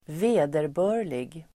Ladda ner uttalet
vederbörlig adjektiv, due Uttal: [²v'e:derbö:r_lig] Böjningar: vederbörligt, vederbörliga Synonymer: passande, rättmätig Definition: som uppfyller rimliga krav (proper, suitable) Exempel: i vederbörlig ordning (in due order)